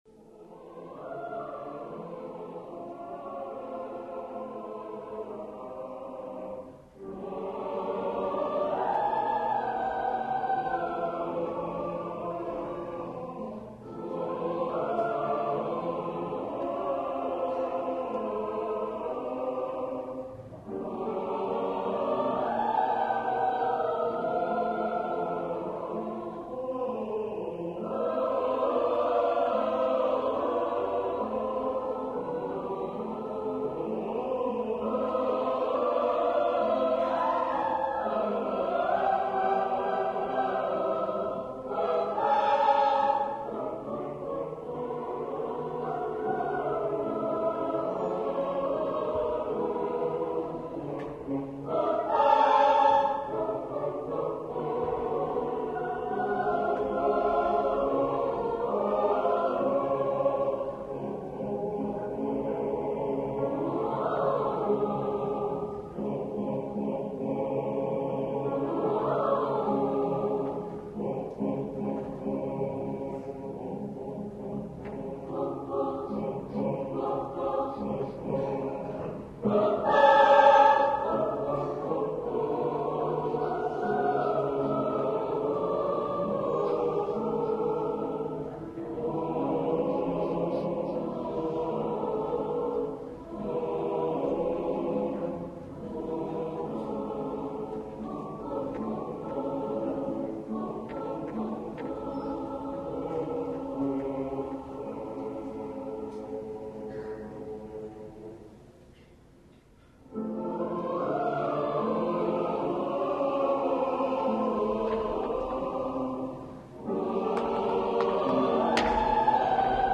mascagni - zanetto - introduzione & coro polifonico.mp3